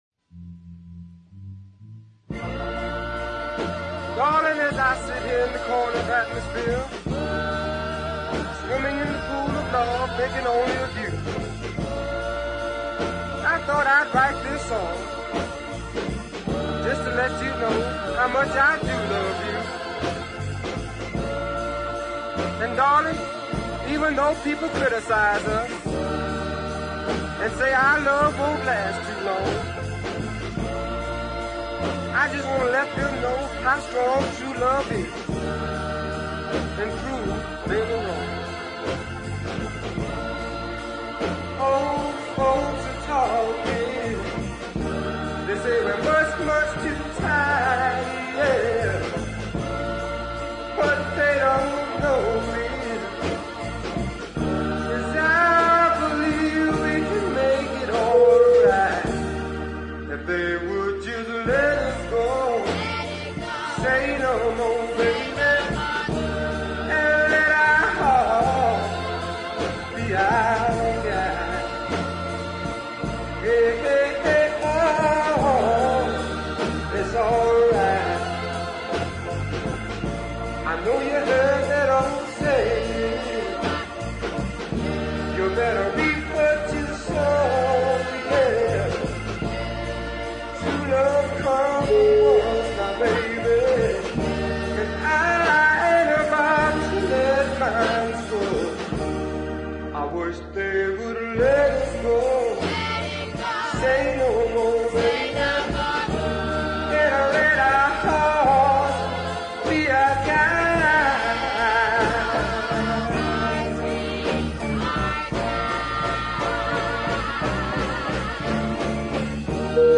and both are strong southern style soul